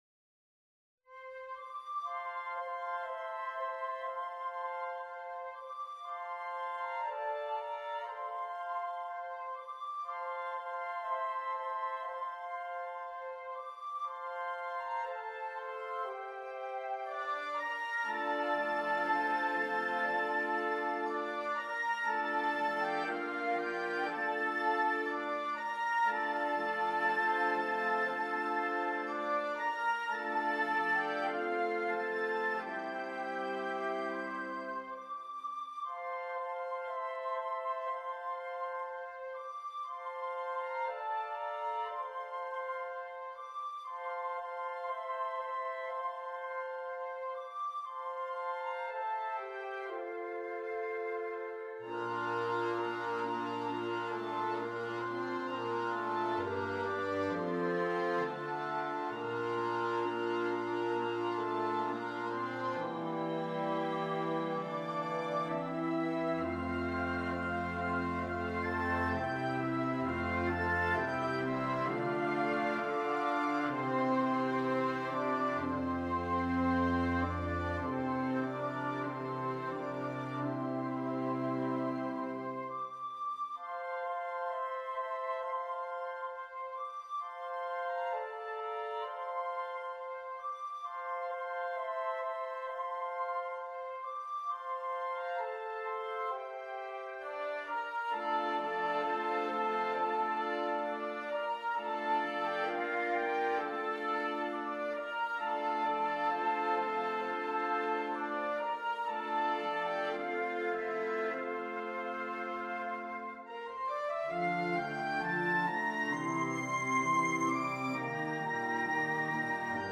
all-woodwind composition
the pure, melodic lines of woodwinds take center stage
This delicate piece